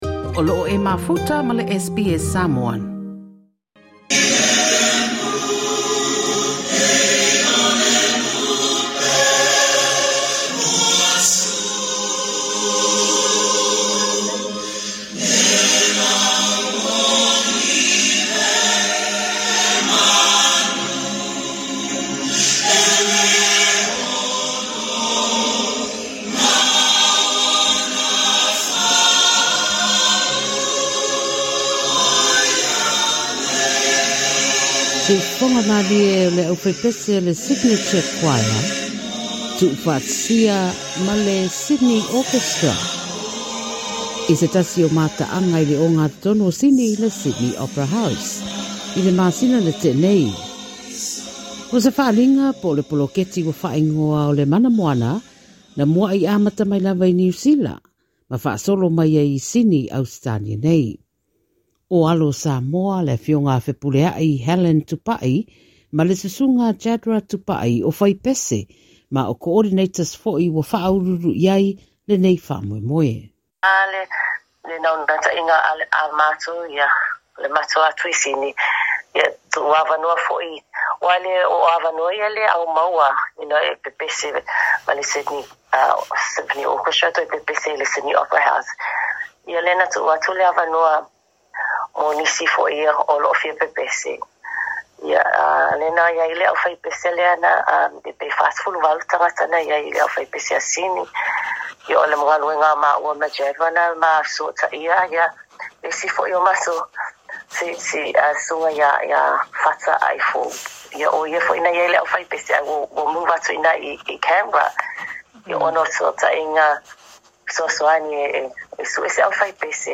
O se koniseti na fa'atumulia i tagata o le Pasefika ma na fa'amalieina i si'ufofoga malie o le 'aufaipese le Signature Choir ma le Sydney Symphony Orchestra i le Opera House i Sini.
Le 'aufaipese Signature Choir ma le Sydney Symphony Orchestra.